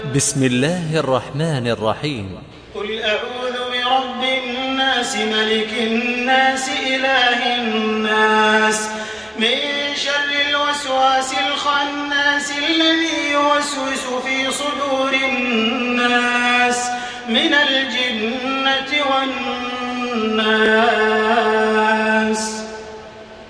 تحميل سورة الناس بصوت تراويح الحرم المكي 1428
مرتل